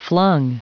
Prononciation du mot flung en anglais (fichier audio)
Prononciation du mot : flung